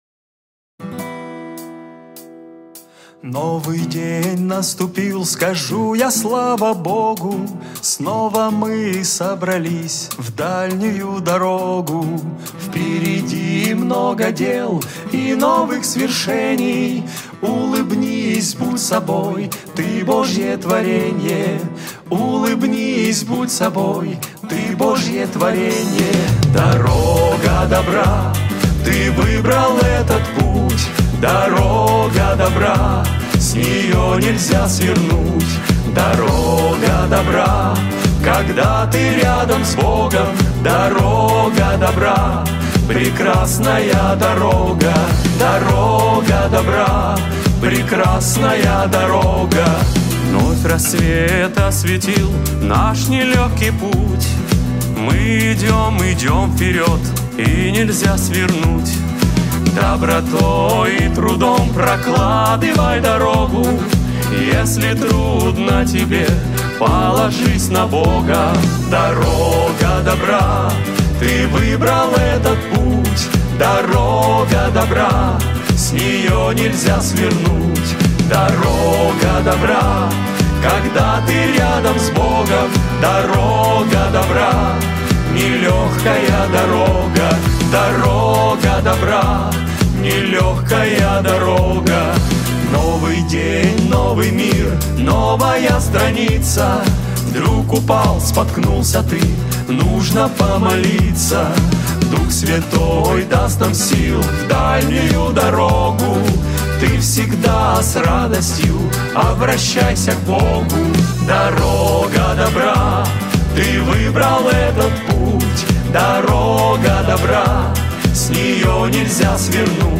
1266 просмотров 806 прослушиваний 199 скачиваний BPM: 103